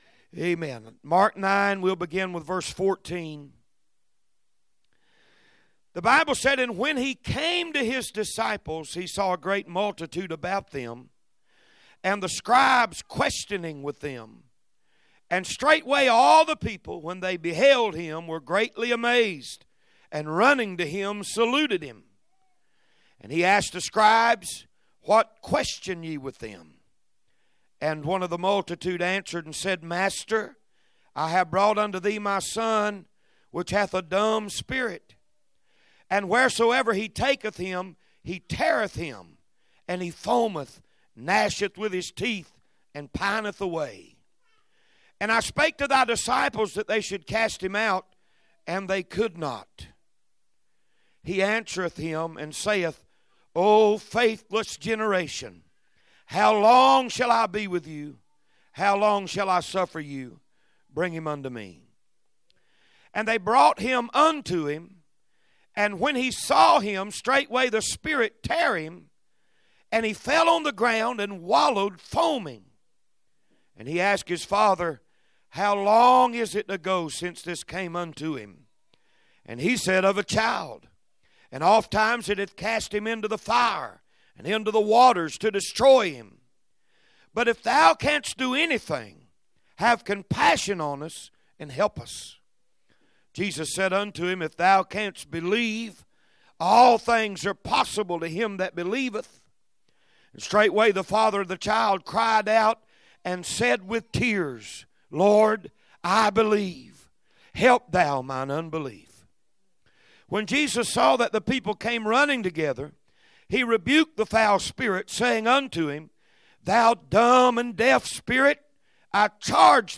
Perfected Love Holiness Church - Sermons Anything Will Help